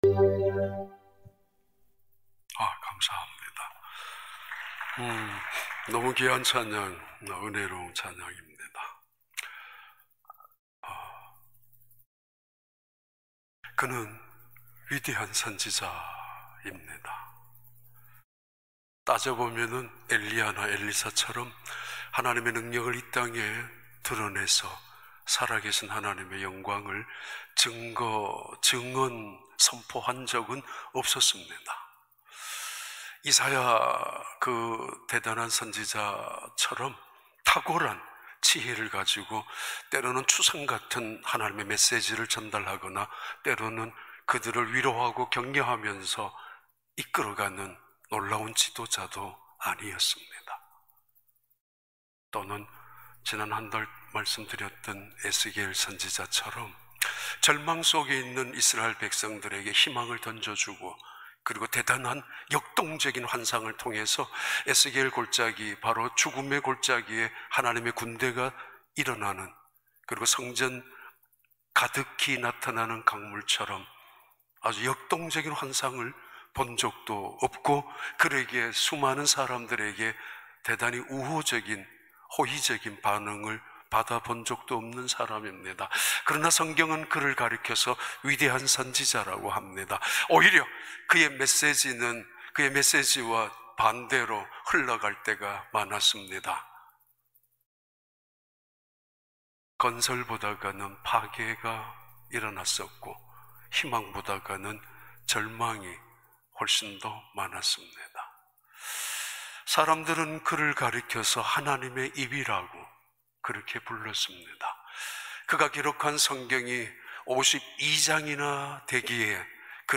2021년 2월 14일 주일 4부 예배